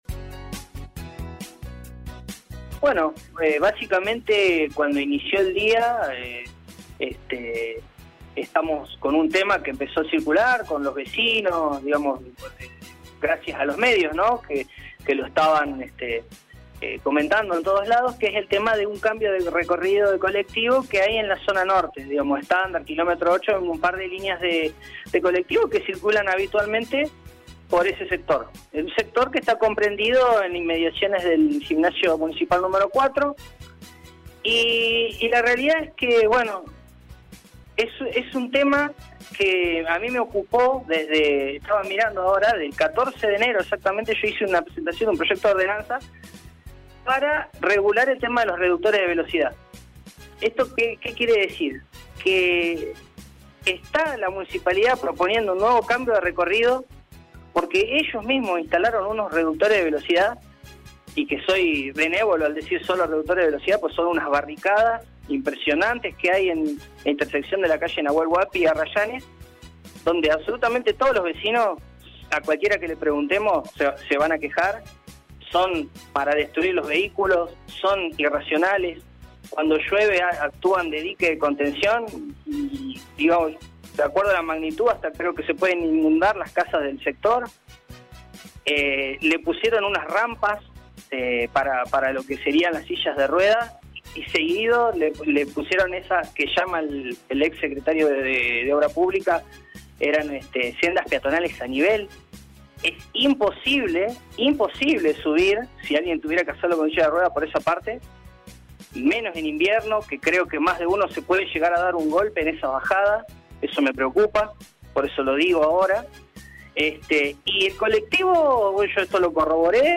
Así lo explicaba el concejal Bustamante en el aire de LA MAÑANA DE HOY: